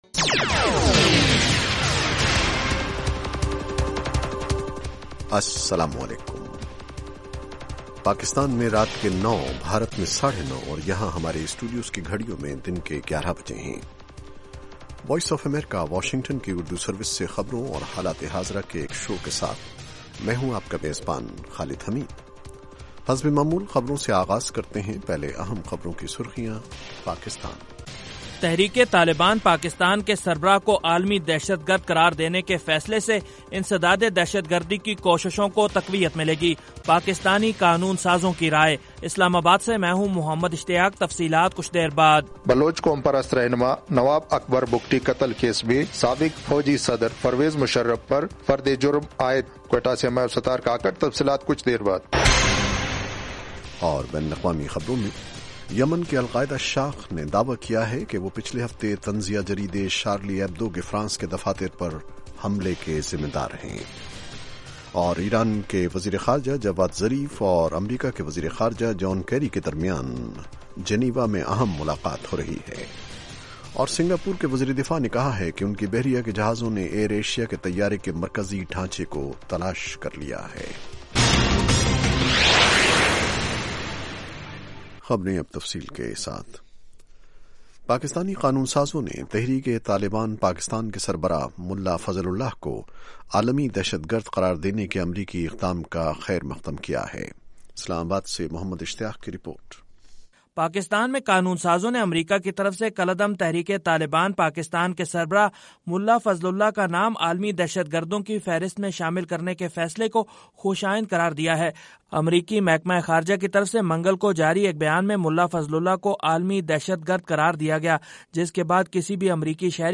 پاکستان اور بھارت سے ہمارے نمائندوں کی رپورٹیں۔ اس کے علاوہ انٹرویو، صحت، ادب و فن، کھیل، سائنس اور ٹیکنالوجی اور دوسرے موضوعات کا احاطہ۔